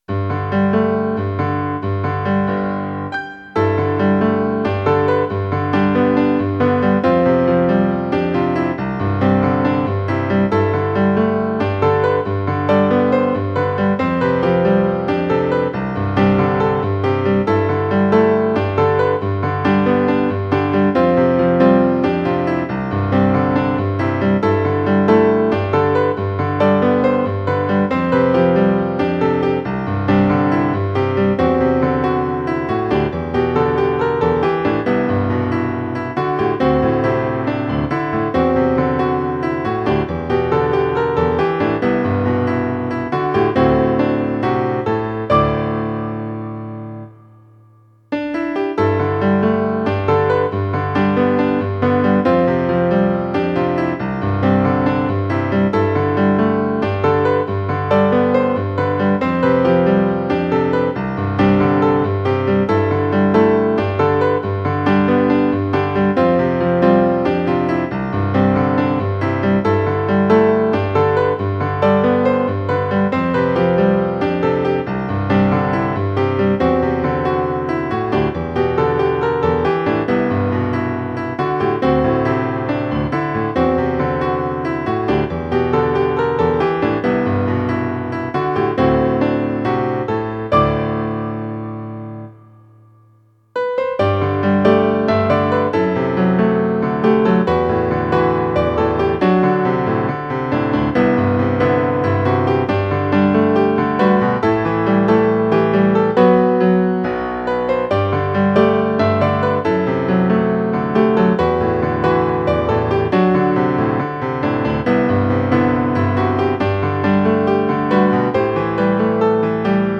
早いテンポのジブリ風ピアノソロを無料音楽素材として公開しています。
イメージ：海辺の道、晴れ　ジャンル：ジブリっぽいピアノソロ
ロンド形式っぽい感じで作っていますので、少し長めです。